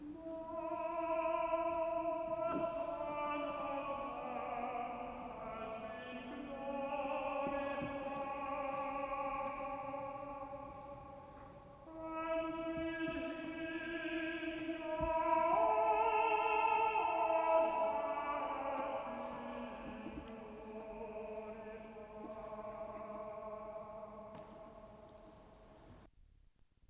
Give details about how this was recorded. Extracts from live recordings